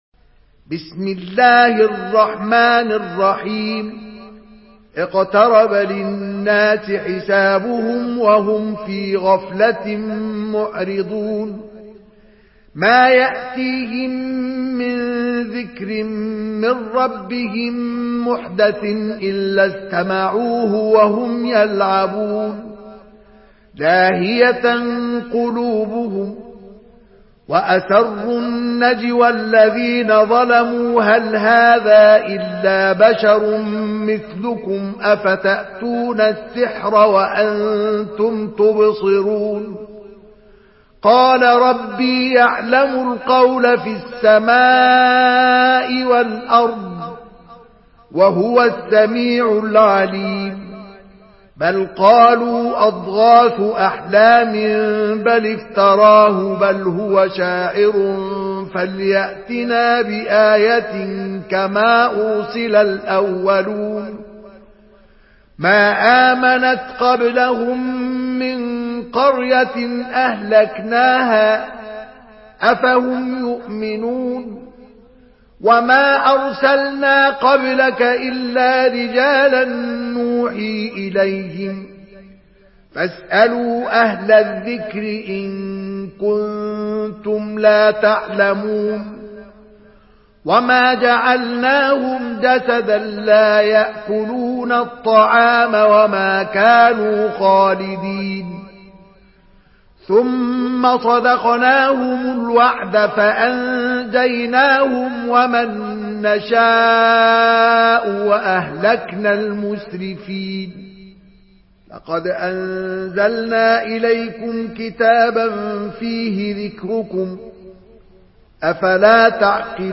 Surah Enbiya MP3 in the Voice of Mustafa Ismail in Hafs Narration
Surah Enbiya MP3 by Mustafa Ismail in Hafs An Asim narration.
Murattal